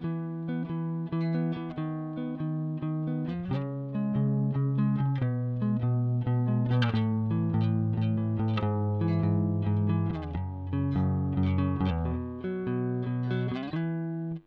Fender Strato����